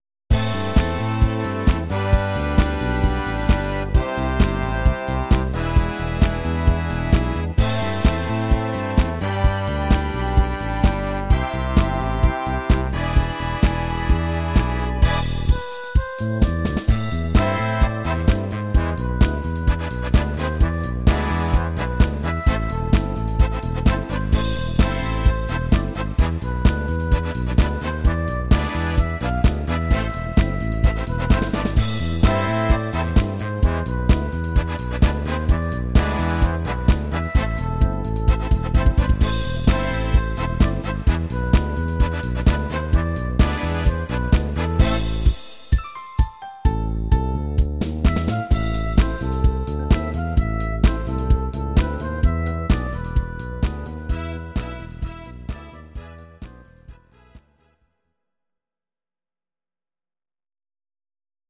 These are MP3 versions of our MIDI file catalogue.
Please note: no vocals and no karaoke included.
Your-Mix: Medleys (1041)